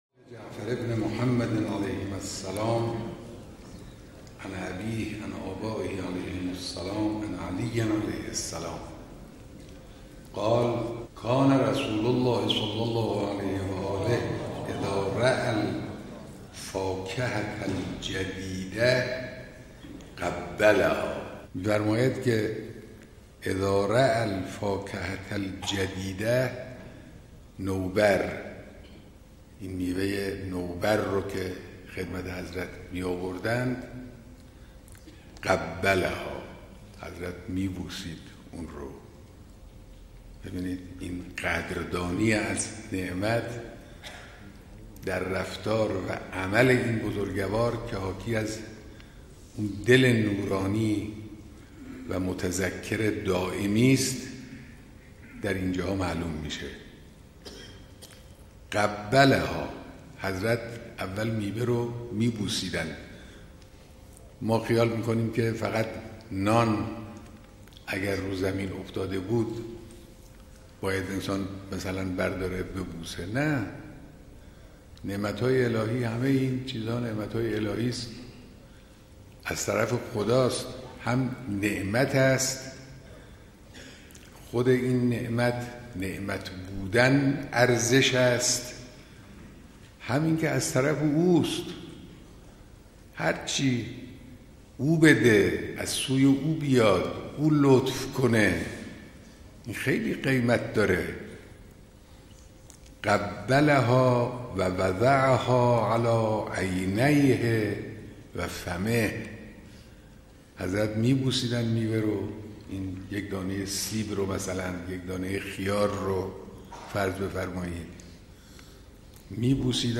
شرح حدیث اخلاقی توسط رهبر / توجه دائمی به نعمت‌های الهی